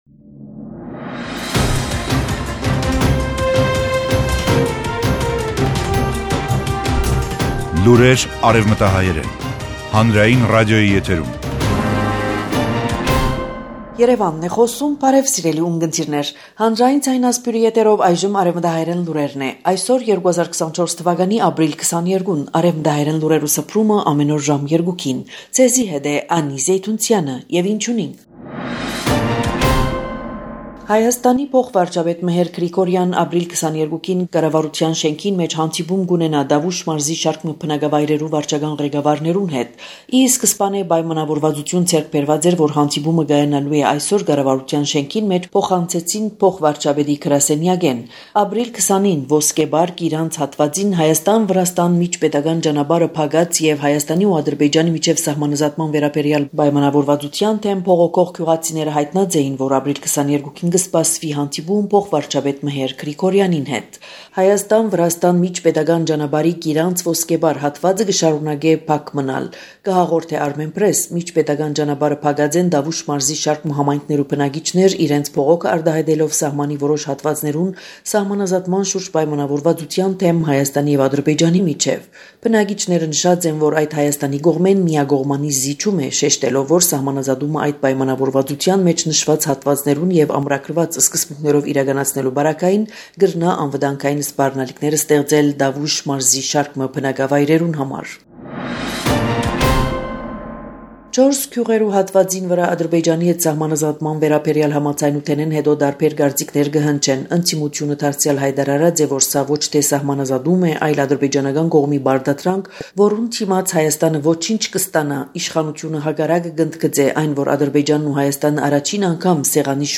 Արեւմտահայերէն լուրեր 22 Ապրիլ. 2024